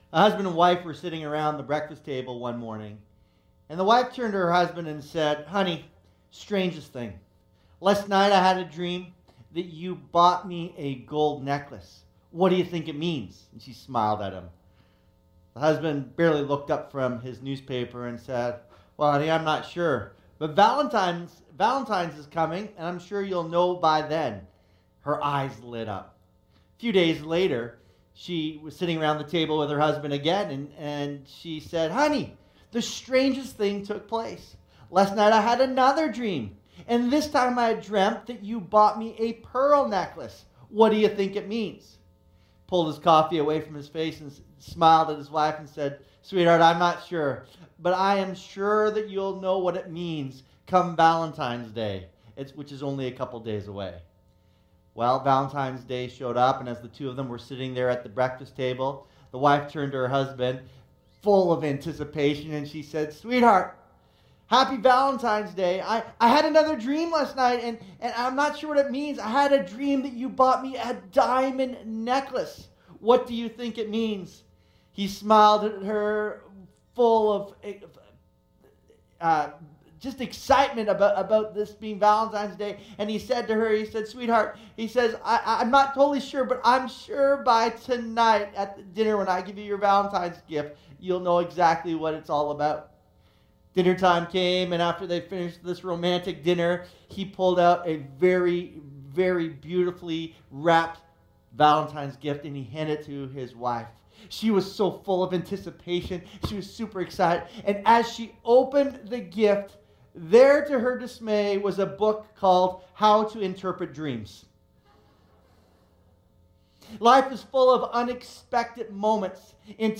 Series: Elijah: Just Like Us Service Type: Sunday Morning Service